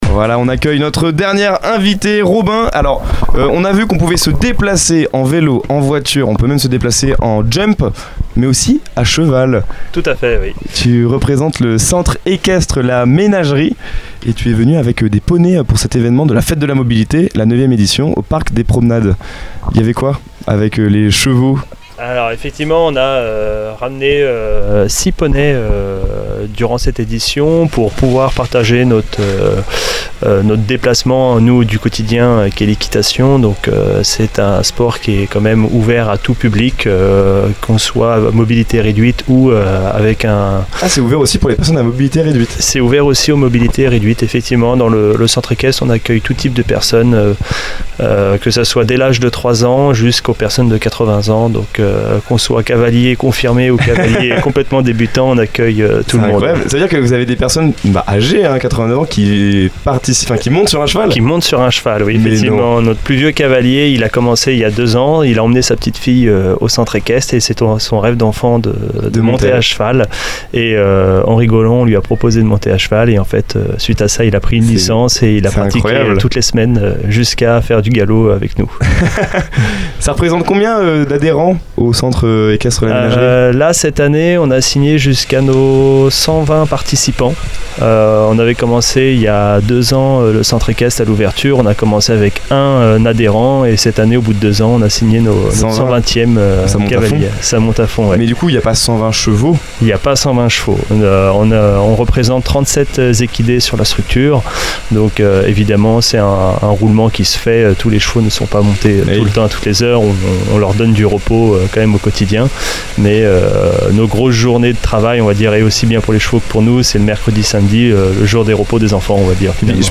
À l’occasion de la Fête de la Mobilité au Parc des Promenades à Alençon, nous étions en direct depuis la Mystery Machine (studio radio aménagé dans une camionnette).